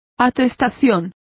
Complete with pronunciation of the translation of attestation.